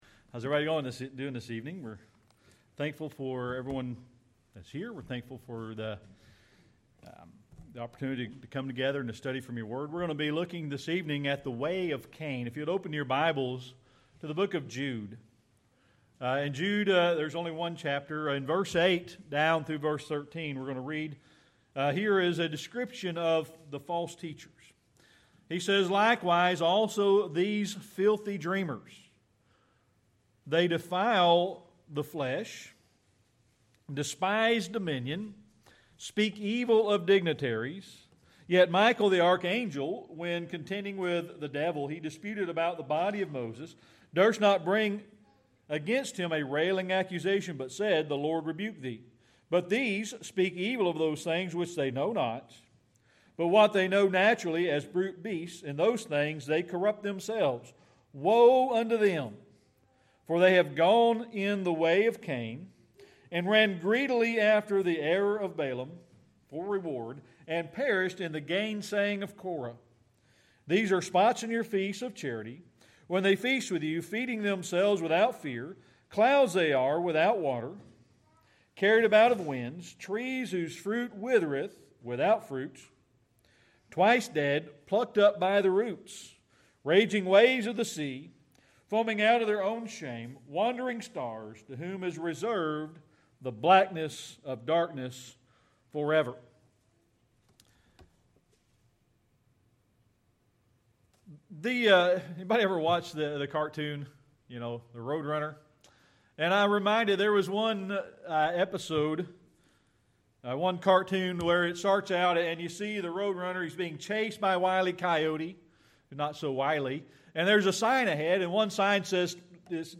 Series: Sermon Archives
Jude 1:11 Service Type: Sunday Evening Worship We're going to be looking at the way of Can.